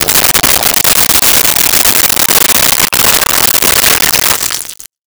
Pour Liquid Into Plastic Cup
Pour Liquid into Plastic Cup.wav